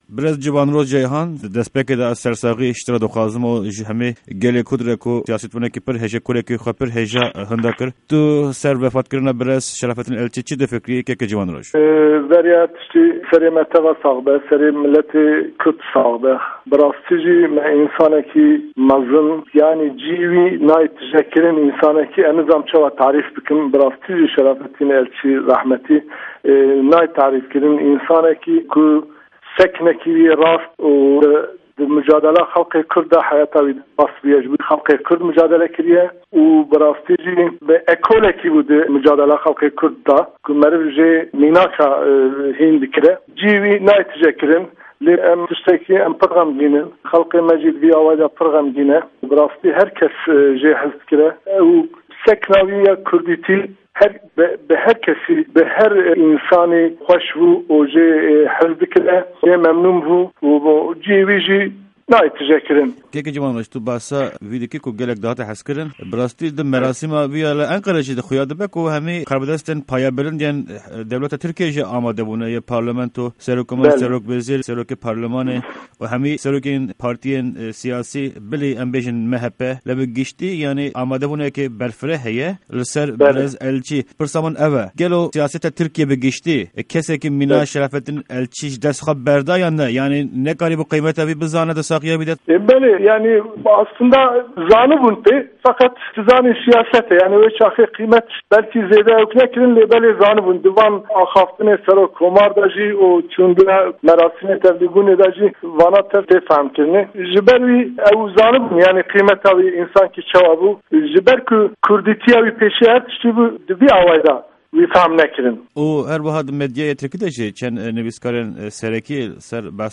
Di hevpeyvîna Pişka Kurdî ya Dengê Amerîka de